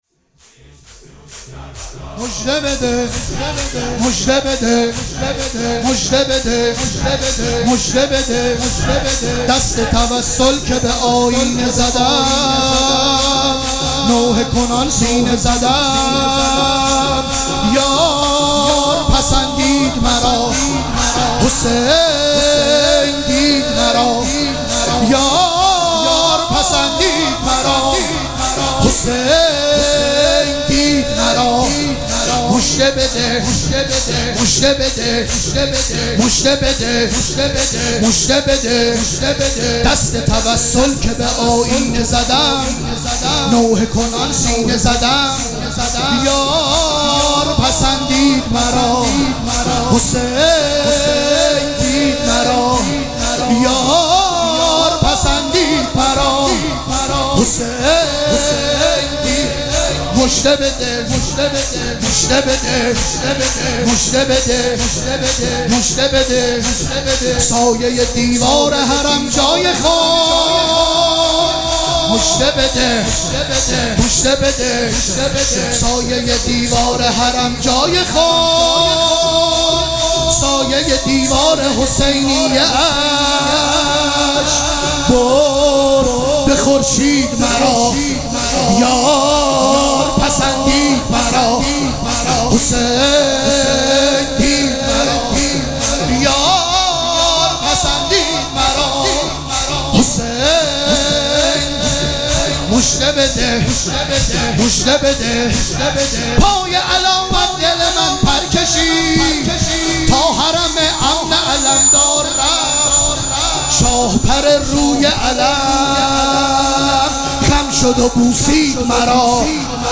مراسم عزاداری شب هشتم محرم الحرام ۱۴۴۷
شور